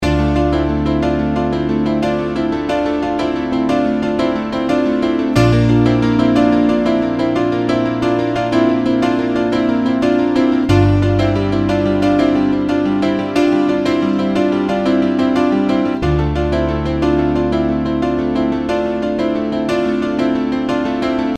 描述：D调钢琴循环曲
Tag: 90 bpm House Loops Piano Loops 3.91 MB wav Key : D